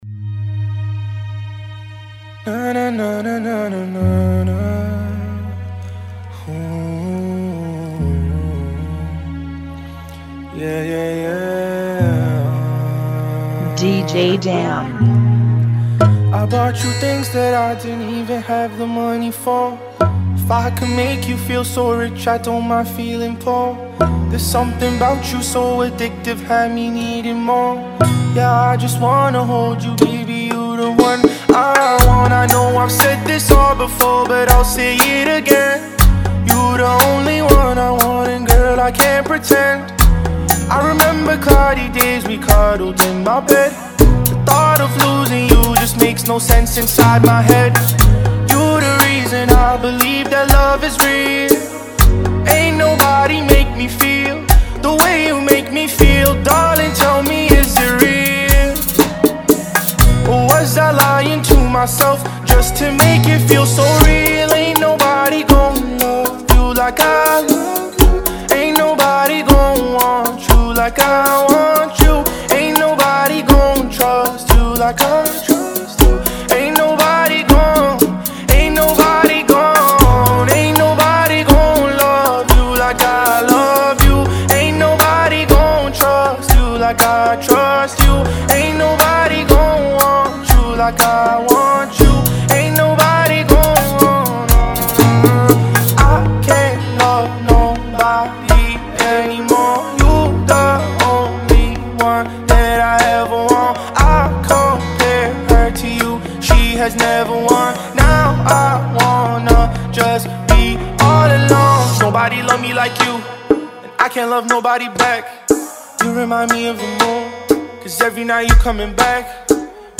120 BPM
Genre: Bachata Remix